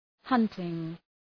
Προφορά
{‘hʌntıŋ}